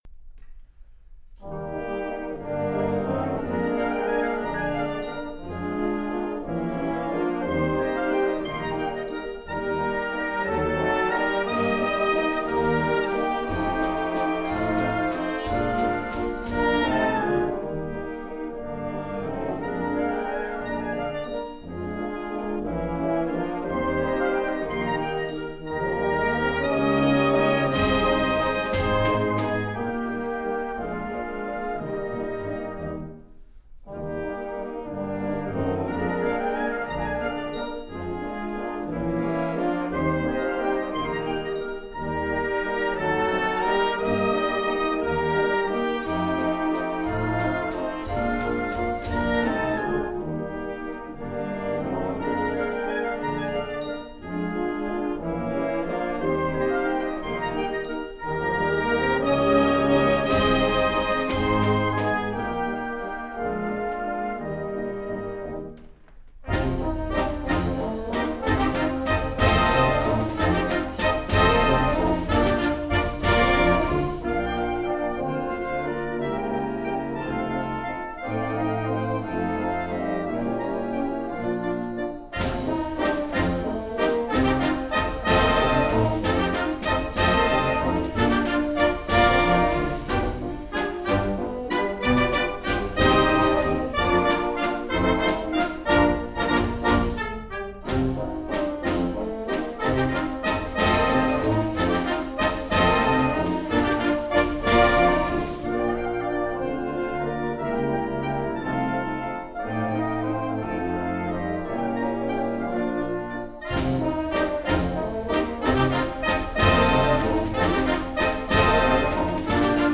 using a pair of Oktava MK-12 omnidirectional
microphones, with an Edirol UA-25 A/D resulting in 24 bit 96KHz master